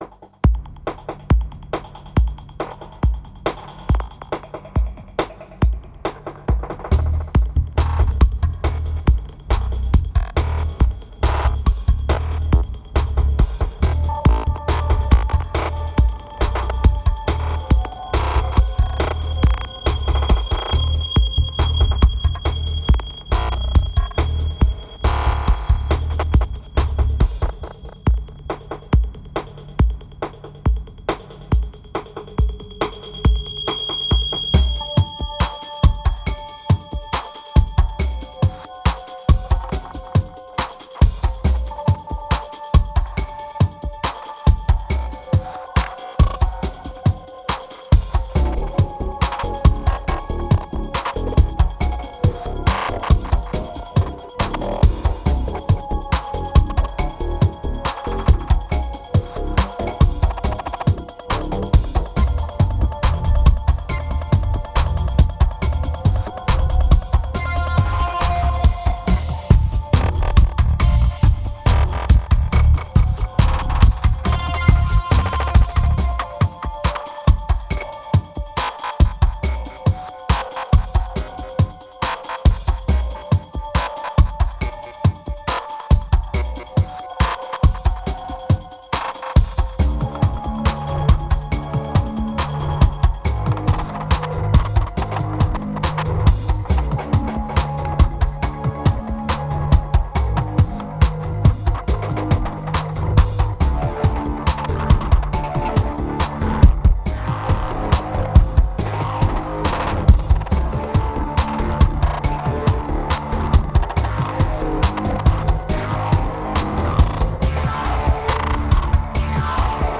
2294📈 - 2%🤔 - 139BPM🔊 - 2009-09-10📅 - -167🌟